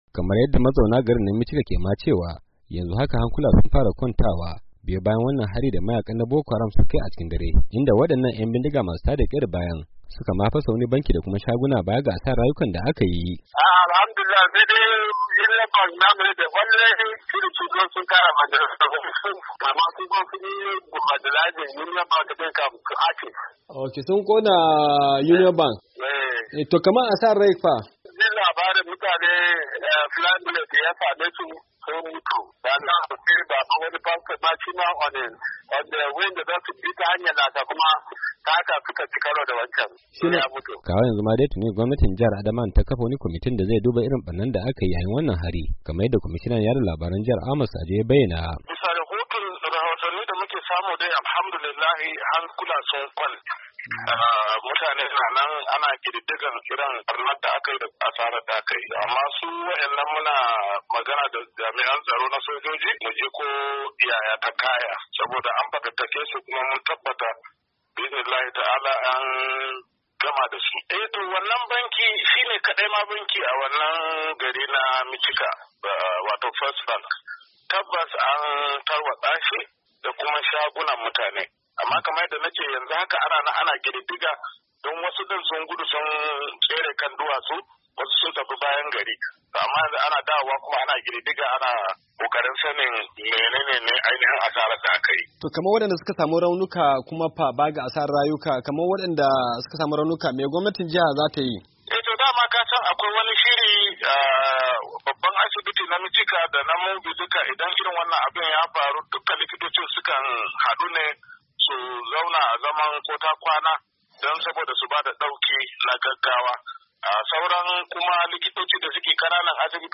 Rahoton harin Michika-3:30"